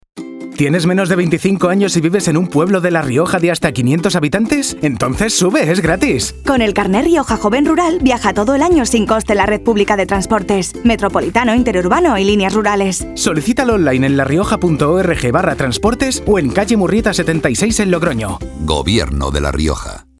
Cuñas radiofónicas
Cuña